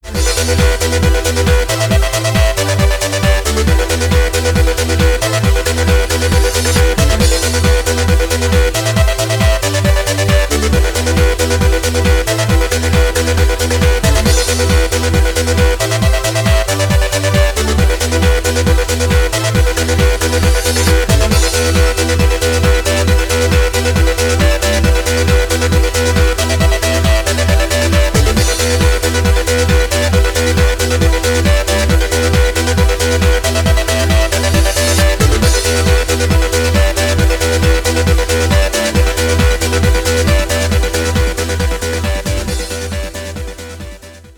• Качество: 320, Stereo
без слов
club
ретро